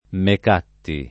[ mek # tti ]